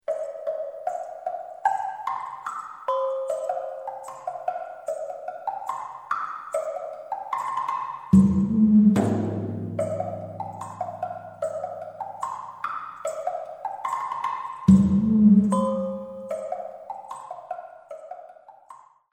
Both CDs contain a variety of all Percussion Music